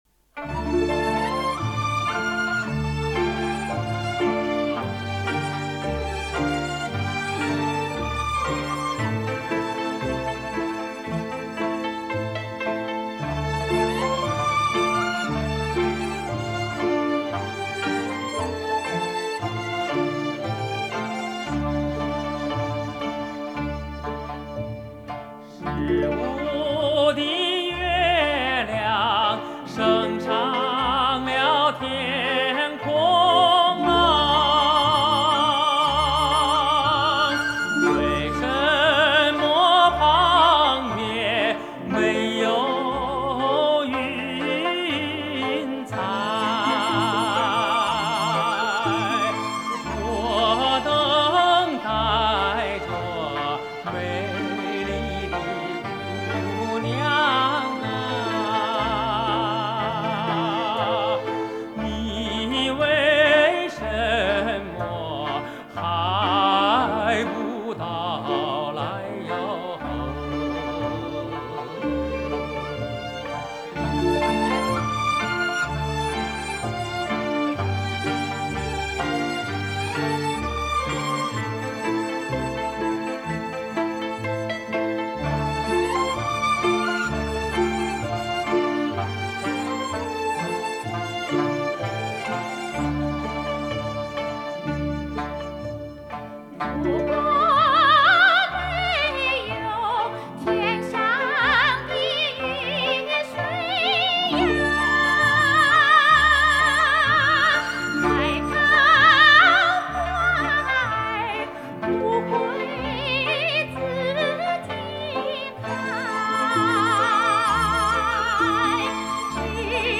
不过，无论如何，这首歌曲还是非常优美的哦。